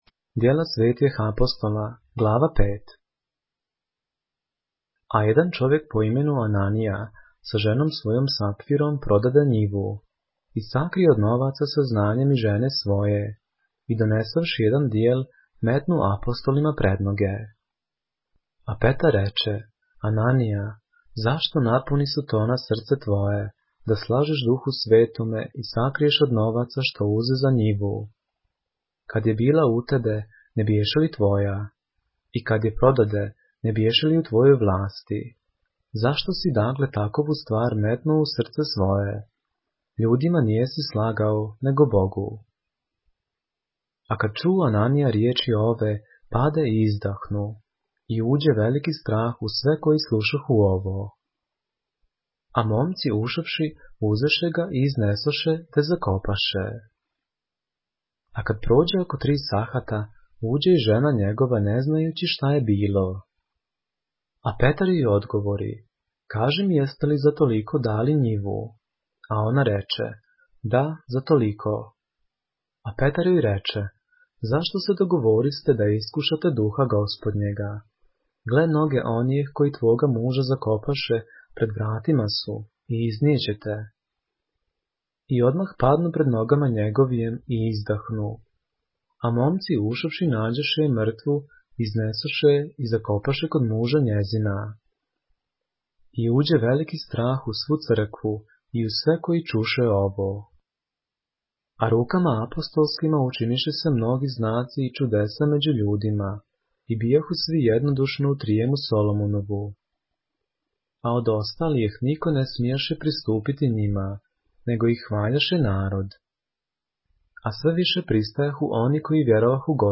поглавље српске Библије - са аудио нарације - Acts, chapter 5 of the Holy Bible in the Serbian language